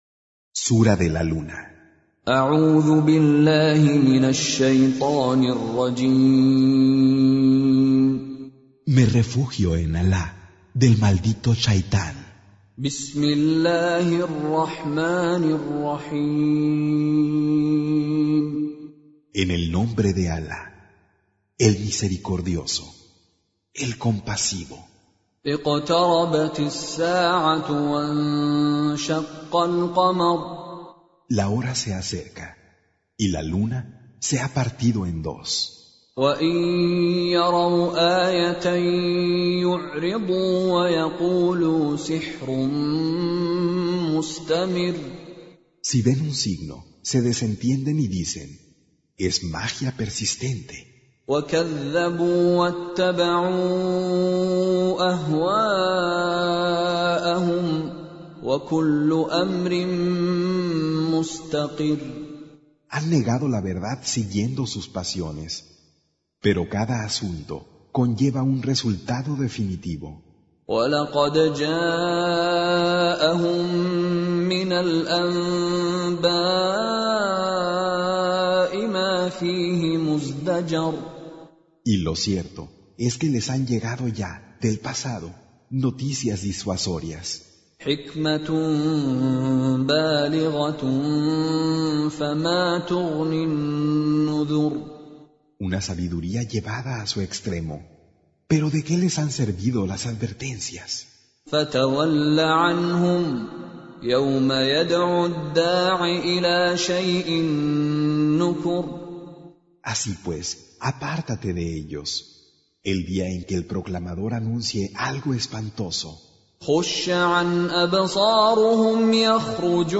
Surah Sequence تتابع السورة Download Surah حمّل السورة Reciting Mutarjamah Translation Audio for 54. Surah Al-Qamar سورة القمر N.B *Surah Includes Al-Basmalah Reciters Sequents تتابع التلاوات Reciters Repeats تكرار التلاوات